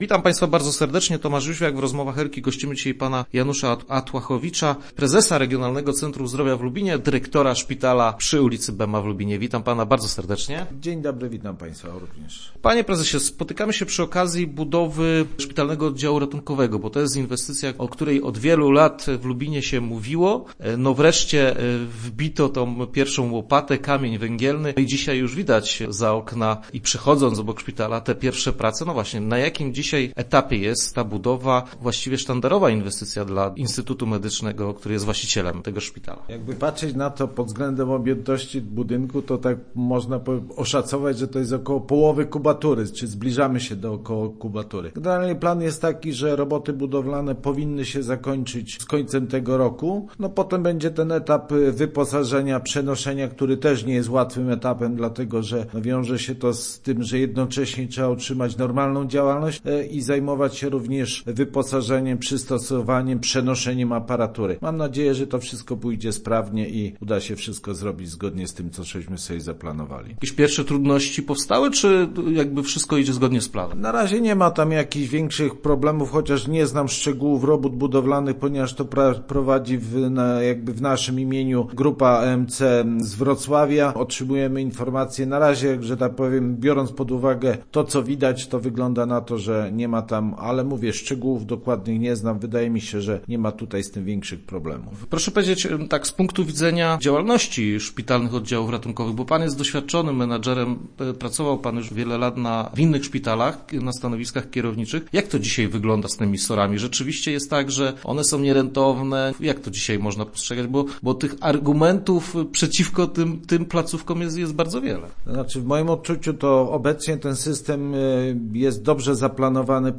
Start arrow Rozmowy Elki arrow Widać już połowę inwestycji